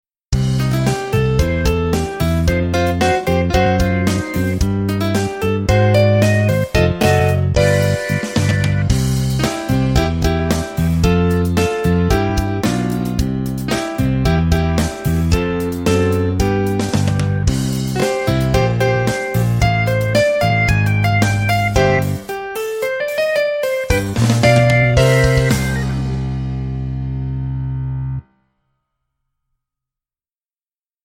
Track 2 Bluesy E